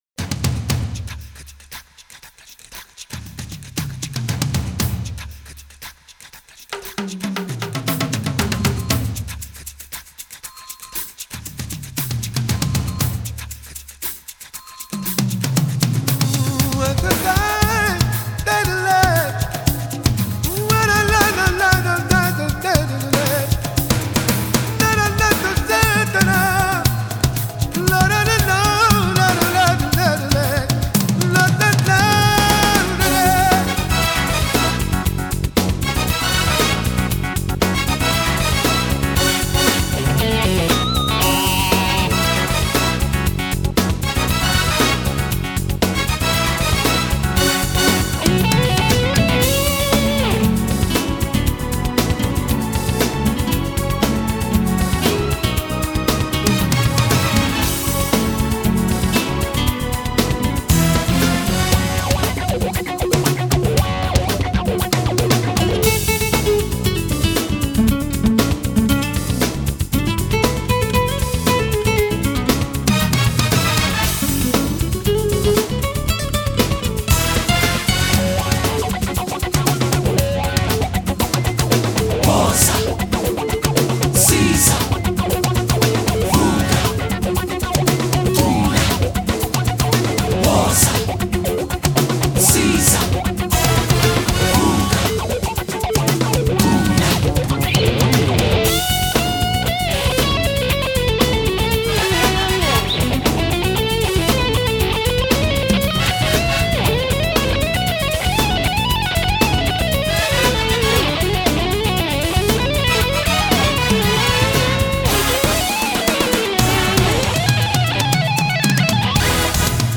Music: Instrumental